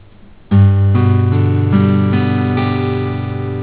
G chord - click to hear soundG chord - click to hear sound
chd_g.wav